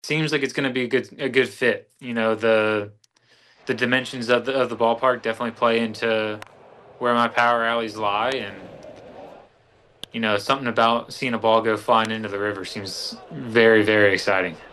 Lowe held a video chat with reporters yesterday and said he is looking forward to splashing a few balls into the Allegheny River.